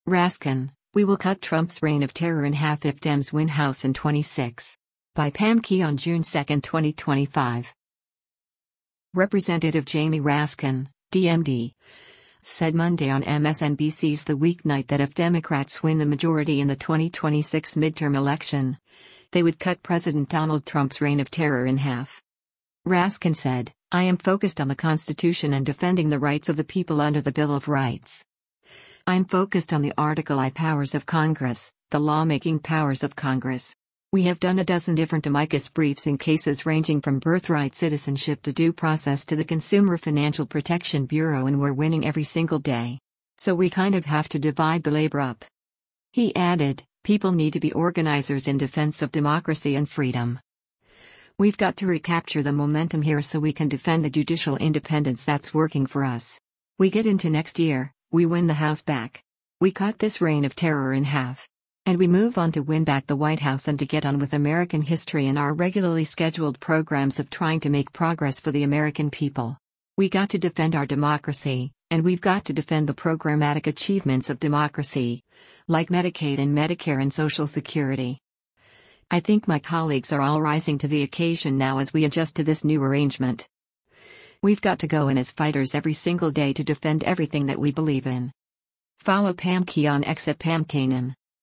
Representative Jamie Raskin (D-MD) said Monday on MSNBC’s “The Weeknight” that if Democrats win the majority in the 2026 midterm election, they would cut President Donald Trump’s “reign of terror in half.”